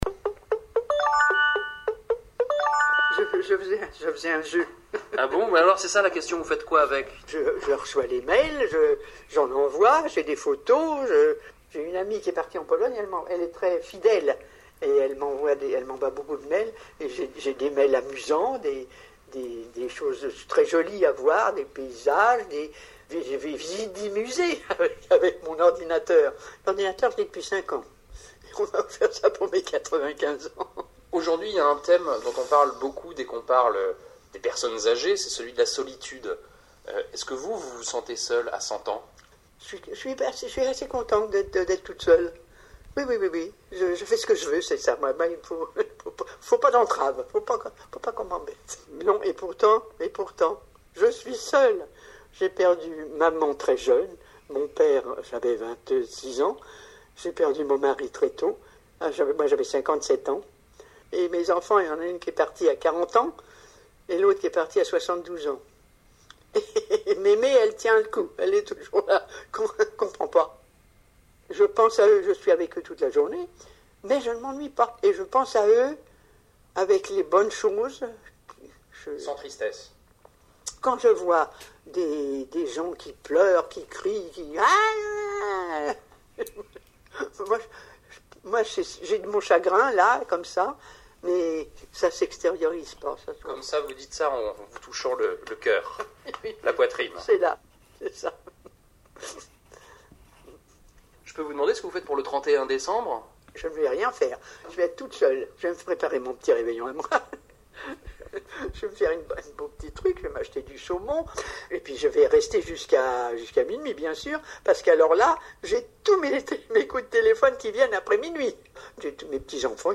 DSC_5022cVoici aujourd’hui une vieille dame qui m’avait bien plu quand je l’avais entendue à la radio.